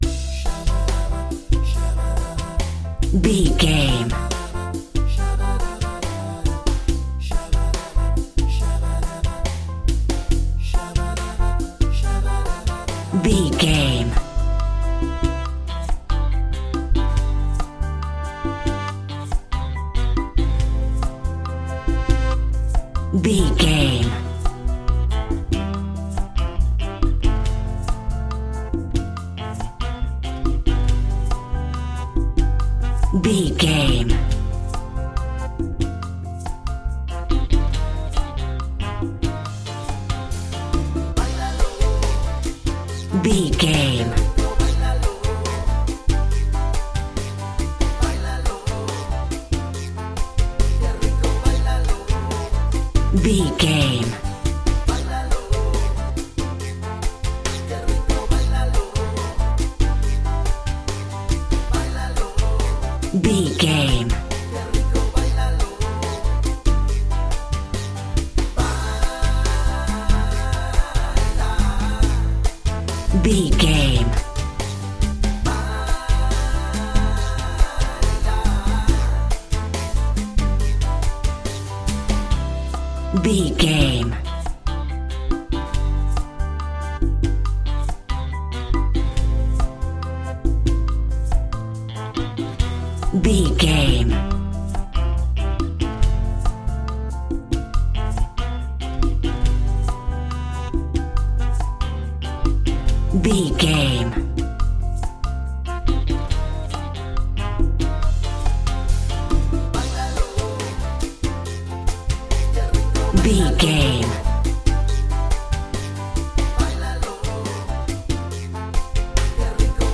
Ionian/Major
flamenco
maracas
percussion spanish guitar